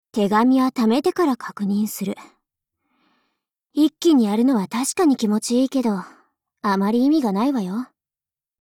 碧蓝航线:U-410语音